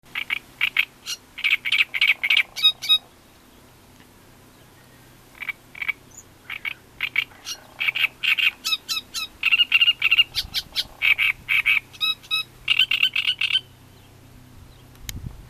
Great Reed Warbler, Acrocephalus arundinaceus
Ziņotāja saglabāts vietas nosaukumsPiņķu ūdenskrātuve
StatusSinging male in breeding season
NotesSēdēja niedrēs. Nebija redzams.